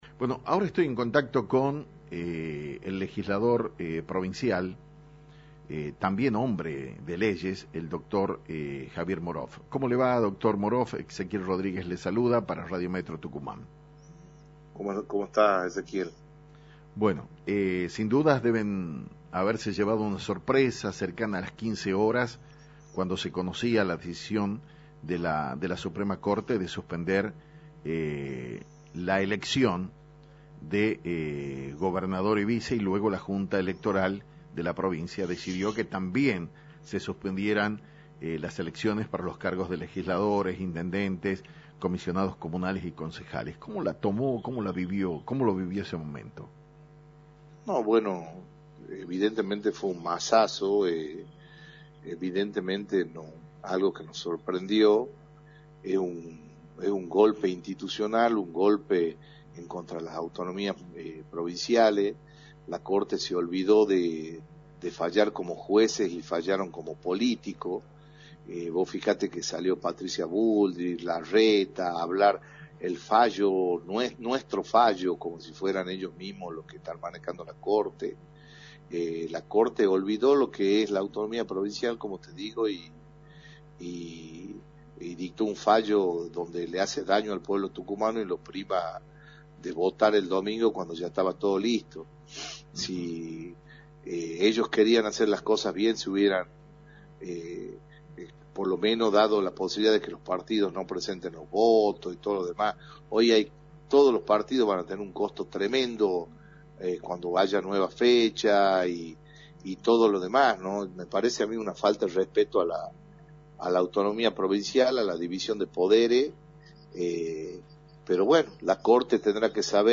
En dialogo
Metro 89.1 mhz, el legislador Dr. Javier Morof hablo acerca de la suspensión de las elecciones en la provincia de Tucuman